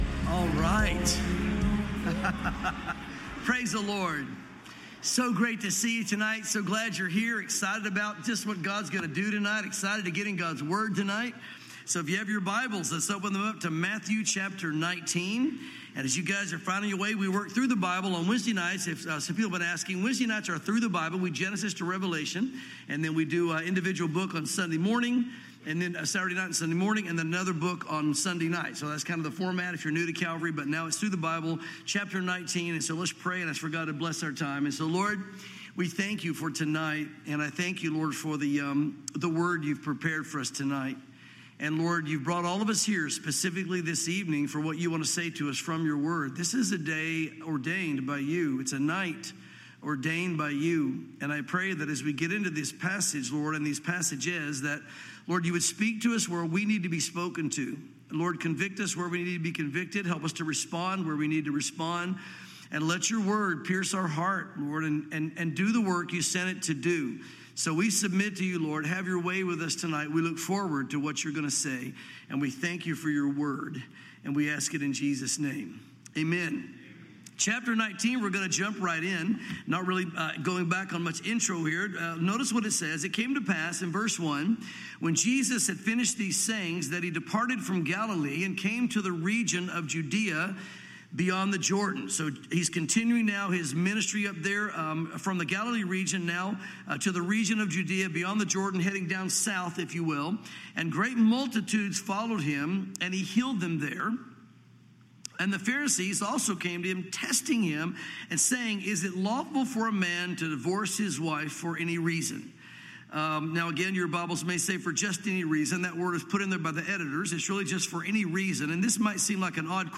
Welcome to Calvary Chapel Knoxville!